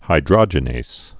(hī-drŏjə-nās, -nāz)